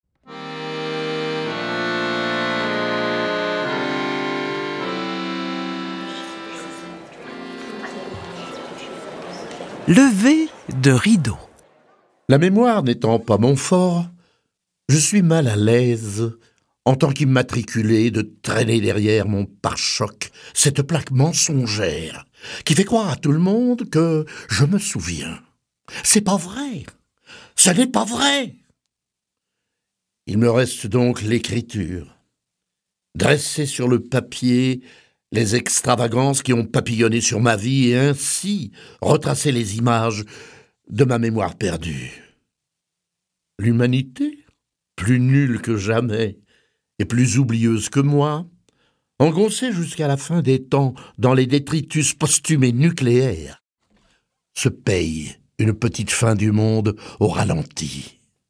Une autobiographie audio d'un des plus grands comédiens et metteurs en scène du Québec. Lu par Paul Buissonneault Durée : 73 min